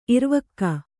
♪ imvakka